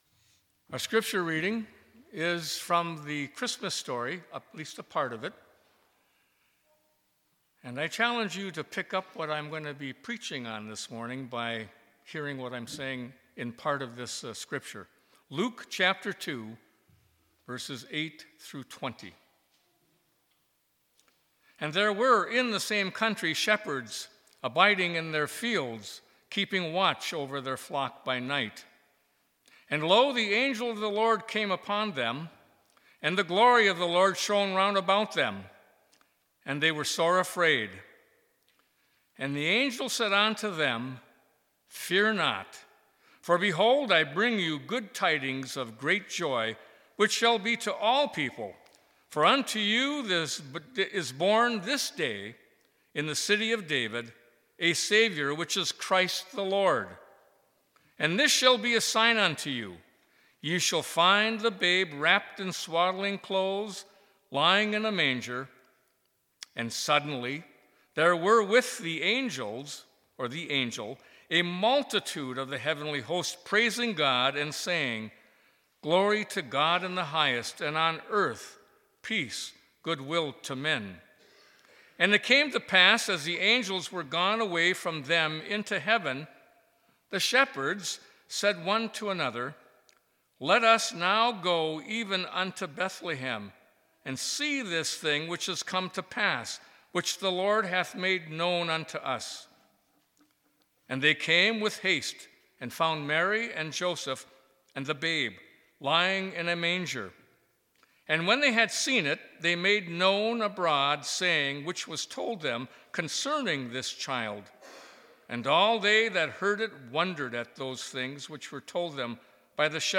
Sermons - ZION